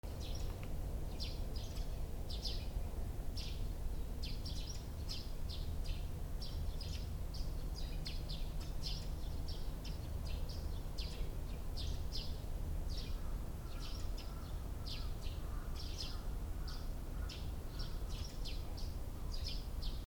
早朝 鳥の声
『チュンチュン』